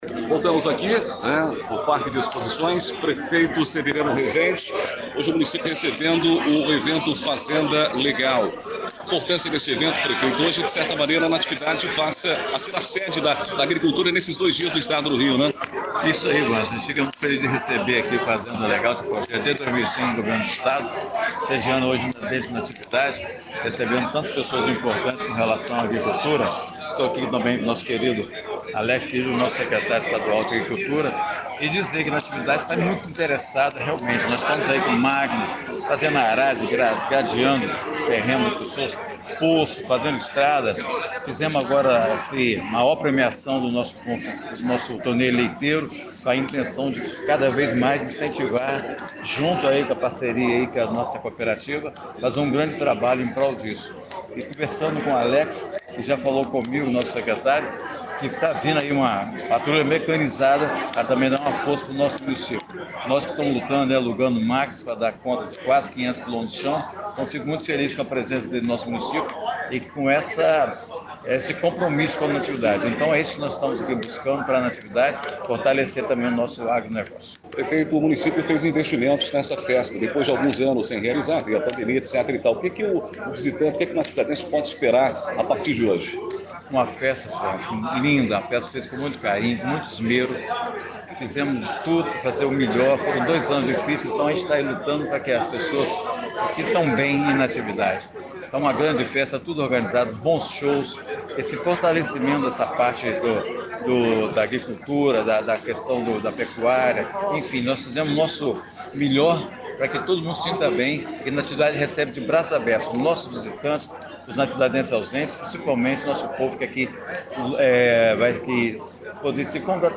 Presentes ao evento, o secretário de estado de Agricultura Alex Grillo, o vice-presidente da ALERJ, deputado Jair Bittencourt, além do prefeito Severiano Rezende, o vice- Thiago do Agudo, falaram à Rádio Natividade.